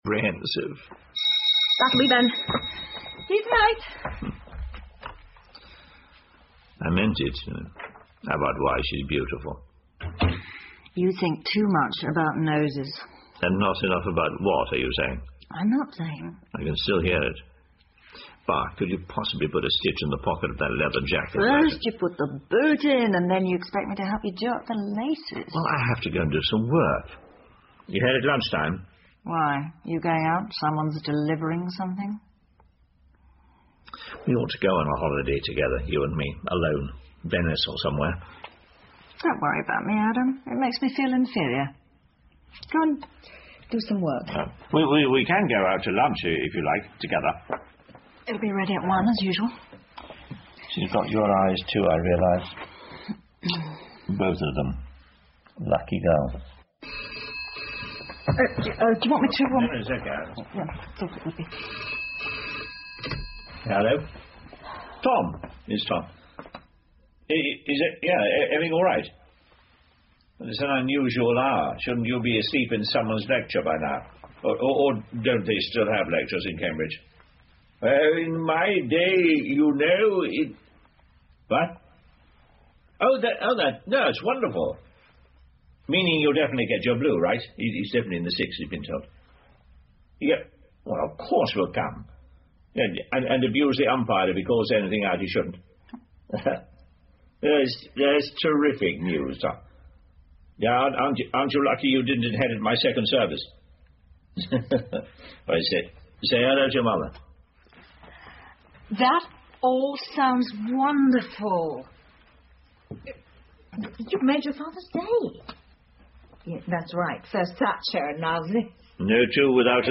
英文广播剧在线听 Fame and Fortune - 6 听力文件下载—在线英语听力室